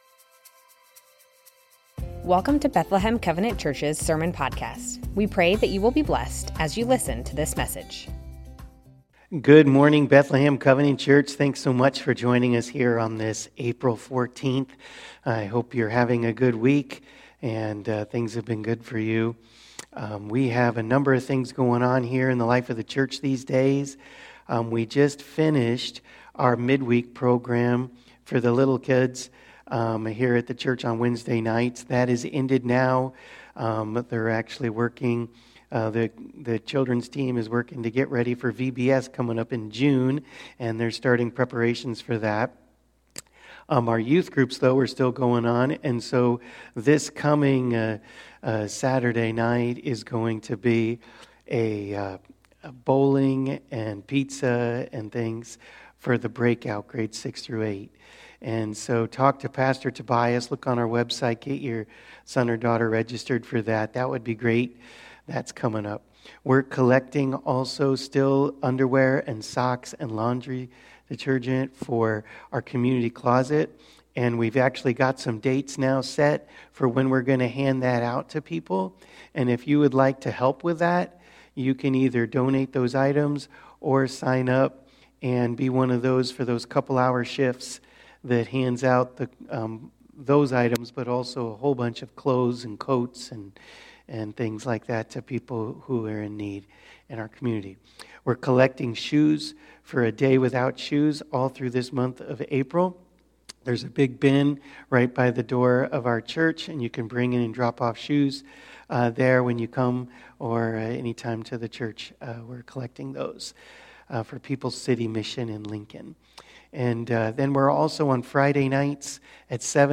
Bethlehem Covenant Church Sermons Still the greatest thing Apr 14 2024 | 00:34:10 1x 00:00 / 00:34:10 Subscribe Share Spotify RSS Feed Share Link Embed